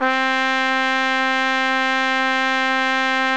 Index of /90_sSampleCDs/Roland L-CD702/VOL-2/BRS_Tpt Cheese/BRS_Cheese Tpt